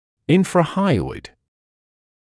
[ˌɪnfrə’haɪɔɪd][ˌинфрэ’хайойд]подподъязычный